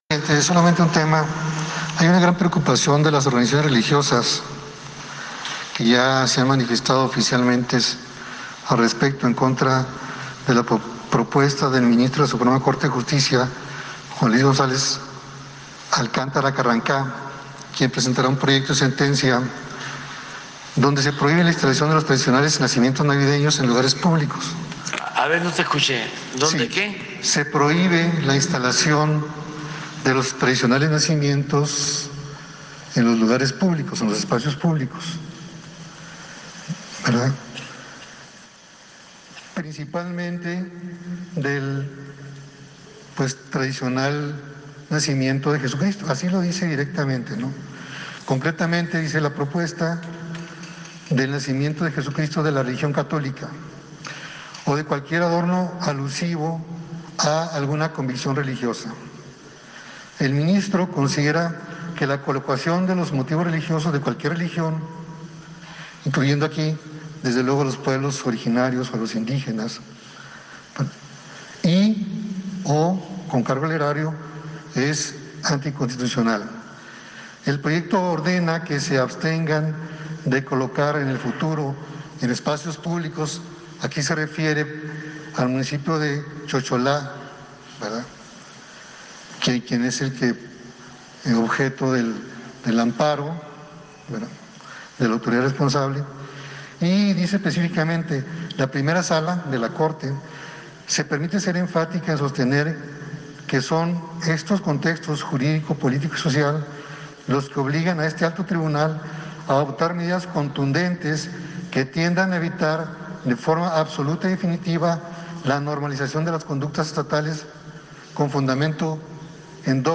Durante la conferencia mañanera en Palacio Nacional, el presidente Andrés Manuel López Obrador fue cuestionado sobre el proyecto, actualmente pospuesto en la SCJN, que contempla prohibir la colocación de nacimientos decembrinos en espacios públicos.